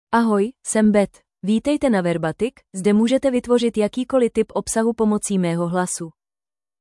FemaleCzech (Czech Republic)
Beth is a female AI voice for Czech (Czech Republic).
Voice sample
Female
Beth delivers clear pronunciation with authentic Czech Republic Czech intonation, making your content sound professionally produced.